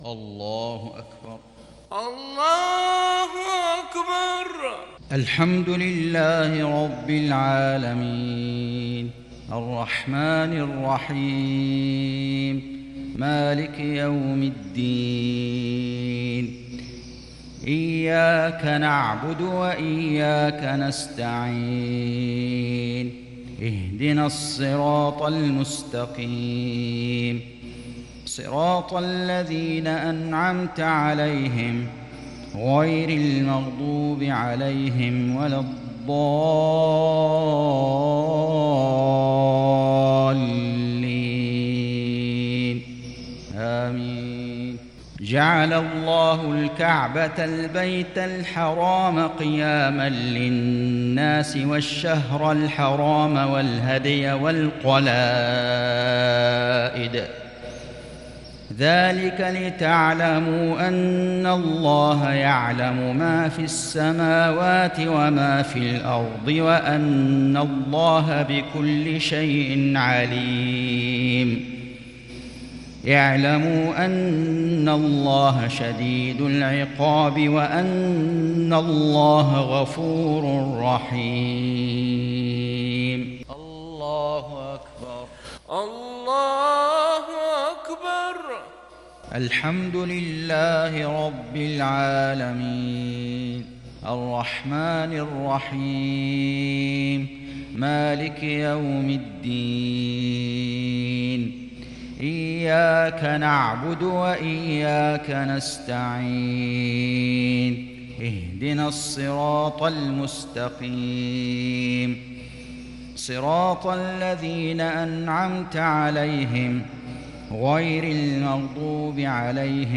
صلاة المغرب للشيخ فيصل غزاوي 17 صفر 1442 هـ
تِلَاوَات الْحَرَمَيْن .